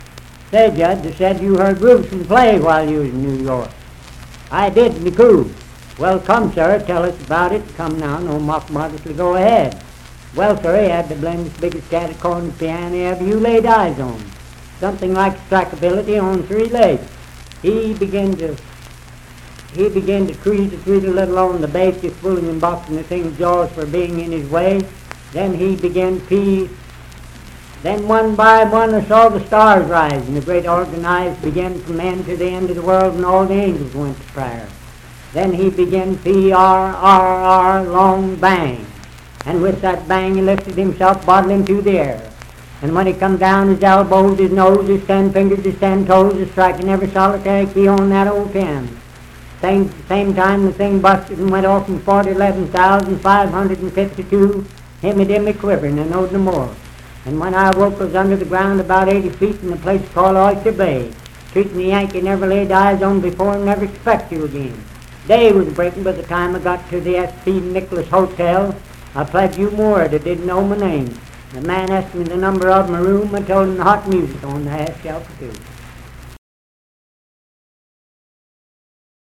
Voice (sung)
Parkersburg (W. Va.), Wood County (W. Va.)